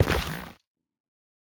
Minecraft Version Minecraft Version 25w18a Latest Release | Latest Snapshot 25w18a / assets / minecraft / sounds / block / netherrack / step5.ogg Compare With Compare With Latest Release | Latest Snapshot
step5.ogg